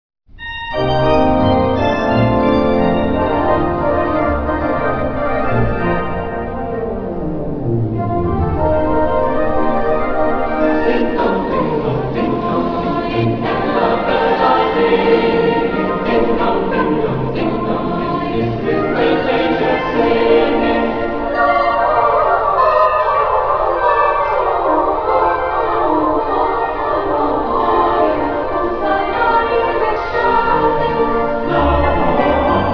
organ.